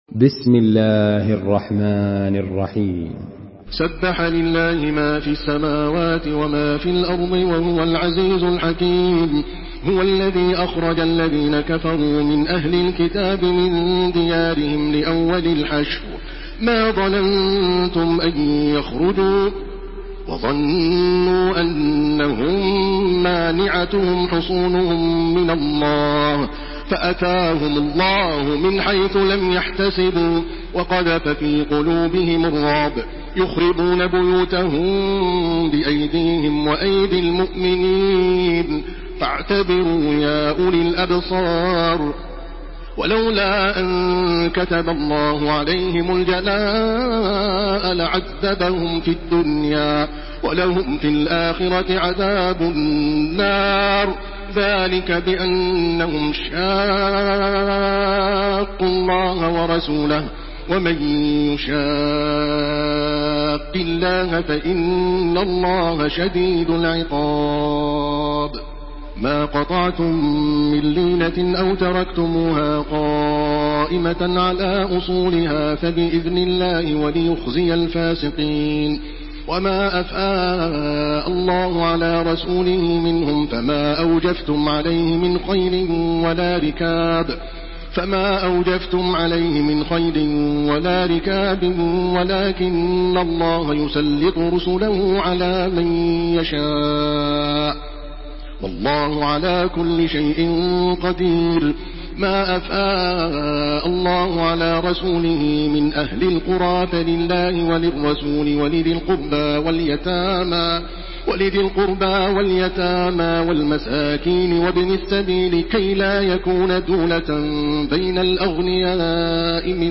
Surah الحشر MP3 by تراويح الحرم المكي 1429 in حفص عن عاصم narration.
مرتل حفص عن عاصم